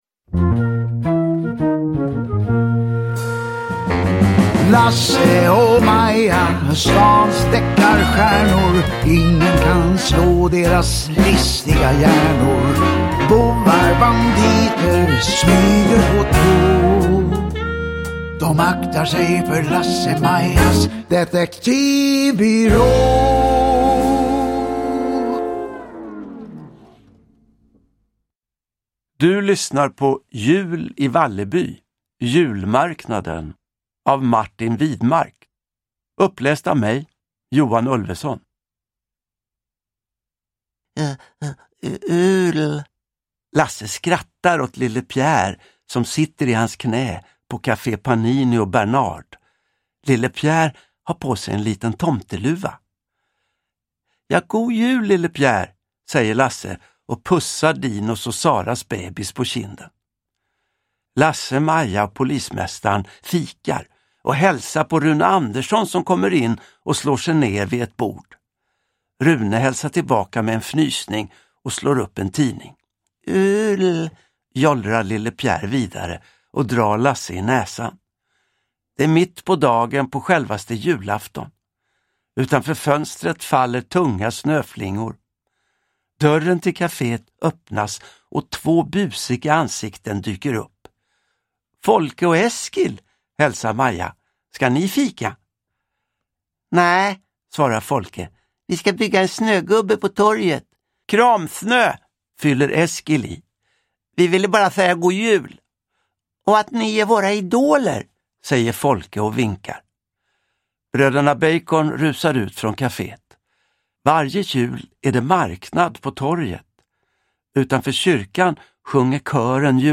Uppläsare: Johan Ulveson
Ljudbok